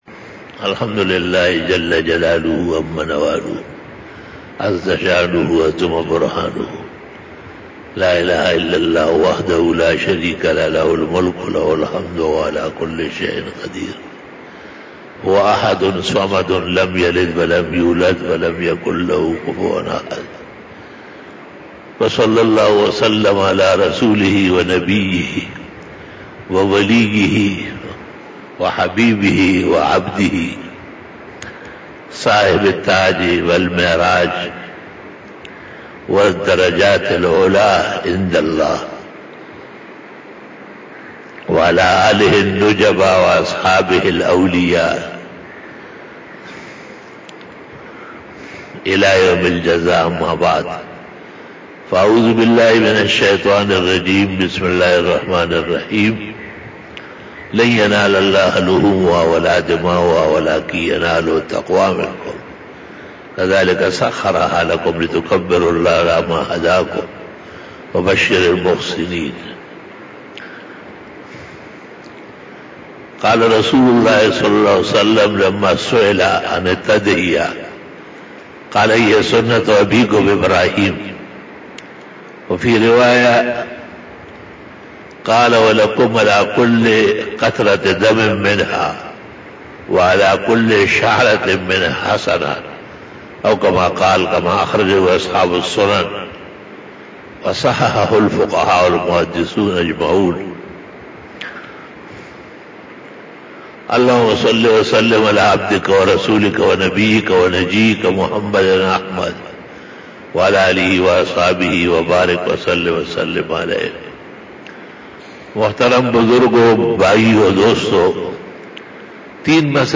22 BAYAN E JUMA TUL MUBARAK 10 July 2020 (18 Zil qaadah 1441H)
Khitab-e-Jummah 2020